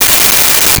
Cell Phone Ring 08
Cell Phone Ring 08.wav